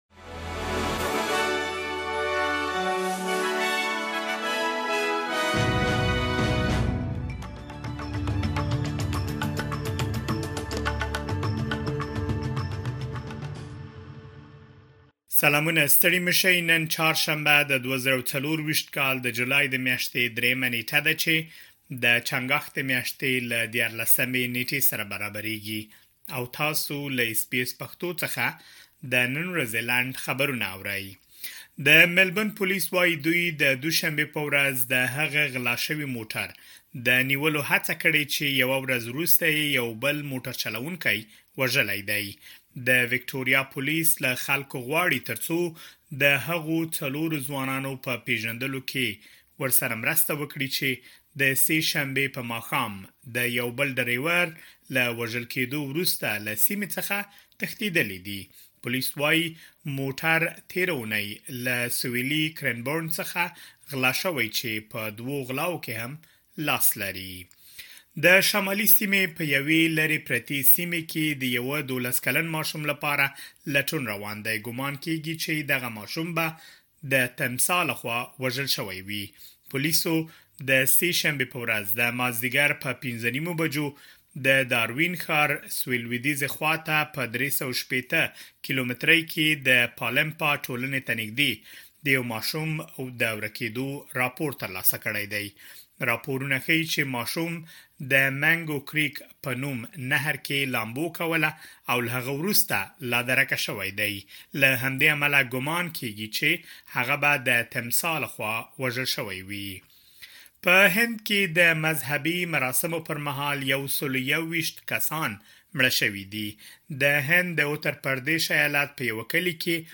د اس بي اس پښتو د نن ورځې لنډ خبرونه|۳ جولای ۲۰۲۴